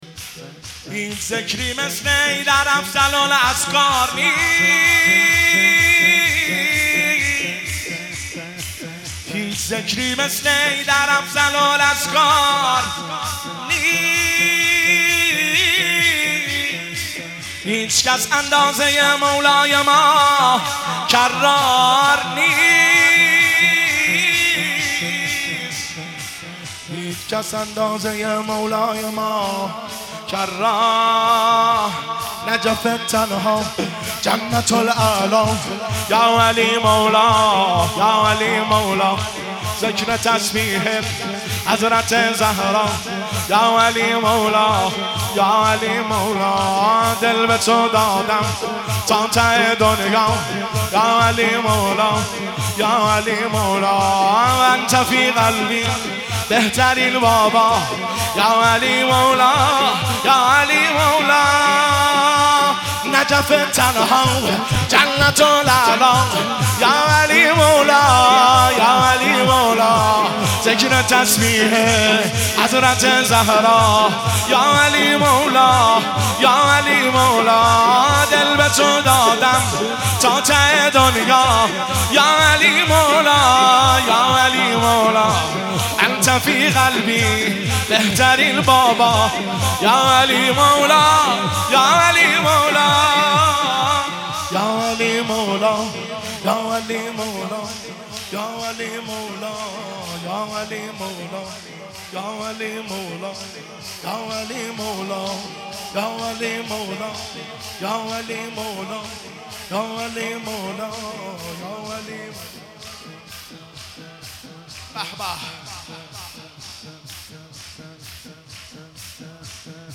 مداحی شور هیچ ذکری مثل حیدر افضل الاذکار نیست
هیئت بین الحرمین طهران